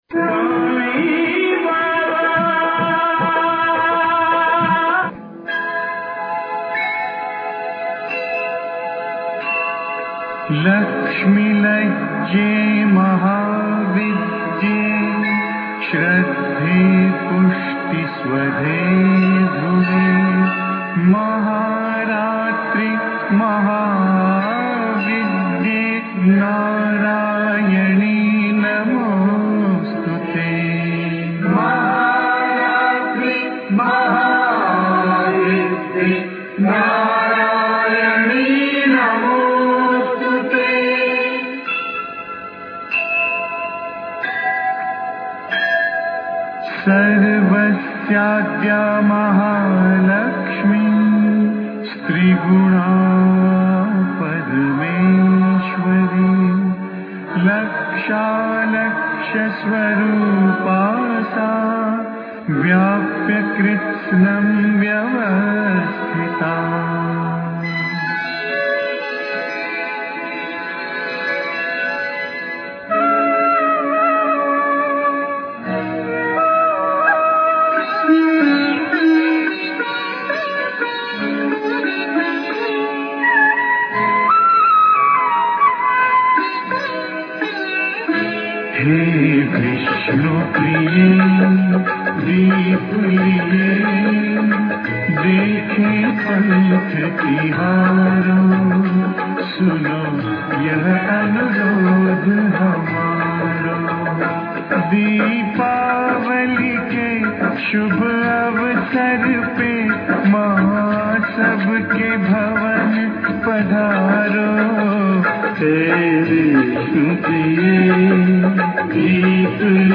Diwali song, telecasts on Sunday, Oct. 22, 2006